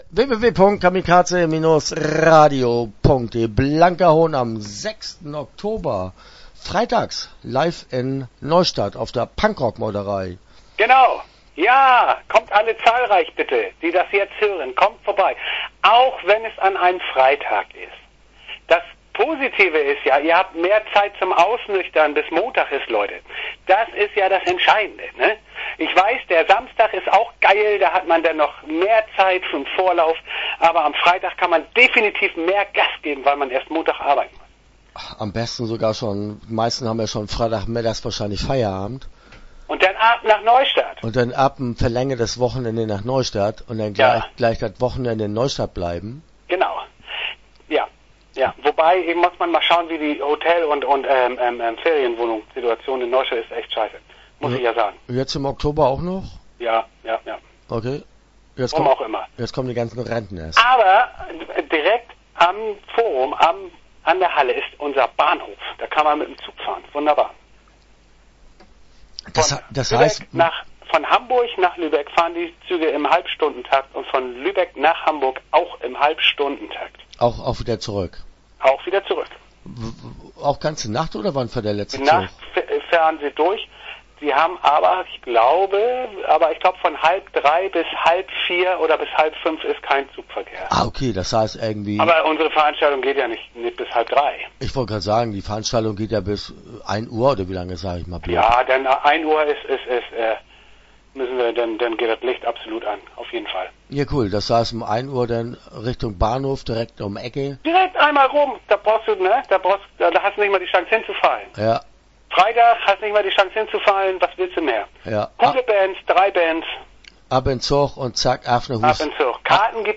Punkrock-Meuterei - Interview Teil 1 (8:36)